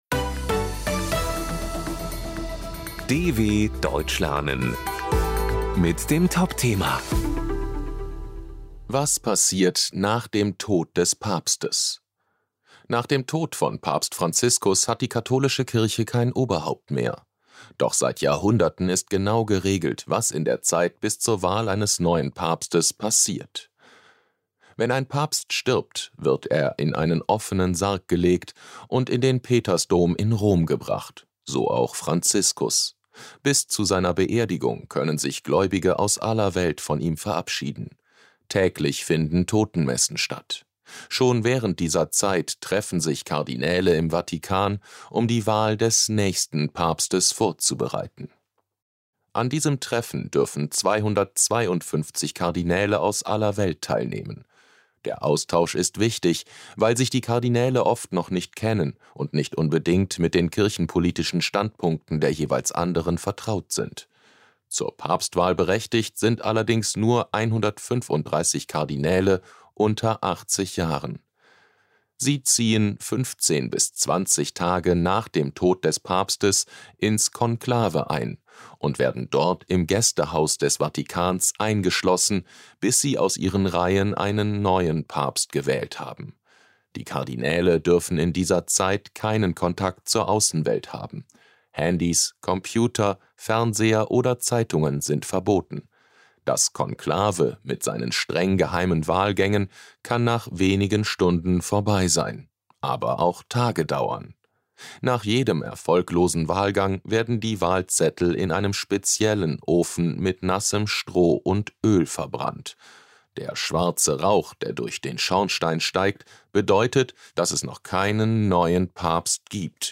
B1 | Deutsch für Fortgeschrittene: Deutsch lernen mit Realitätsbezug: aktuelle Berichte der Deutschen Welle – leicht verständlich und mit Vokabelglossar.